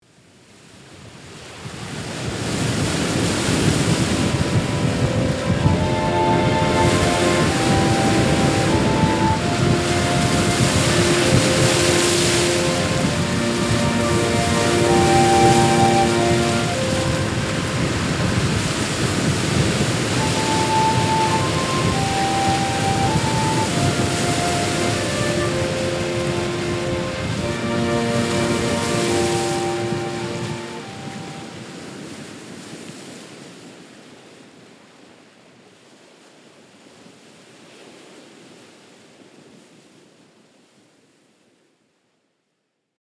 Layered with ocean waves, one example of these transitions is below.
Music and Waves
Music-and-Waves-Transition.mp3